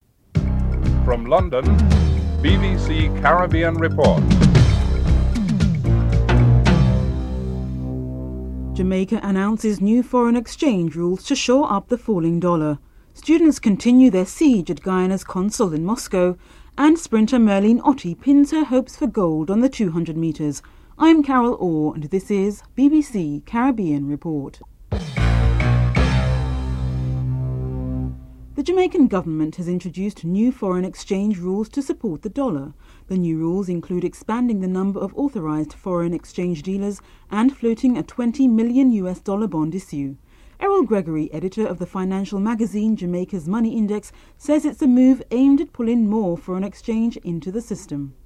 Headlines (00:00-00:35)